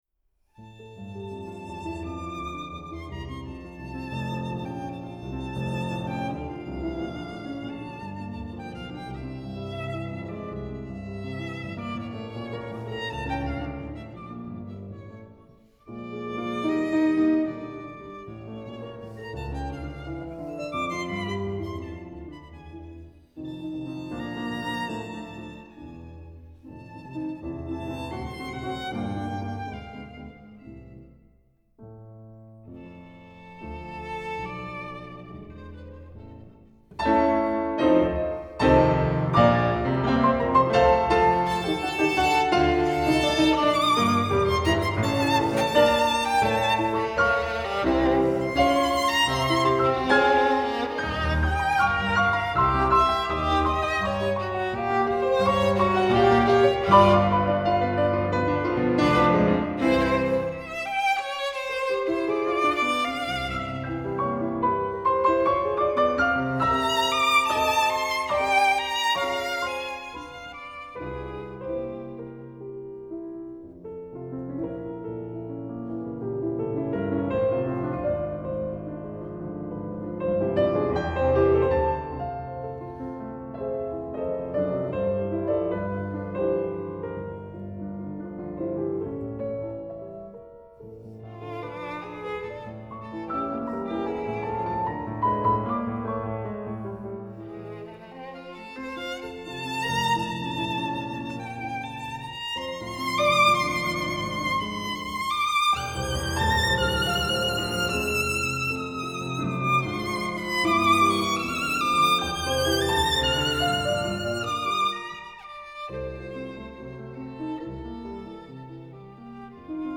Sonata for Violin and Piano No. 3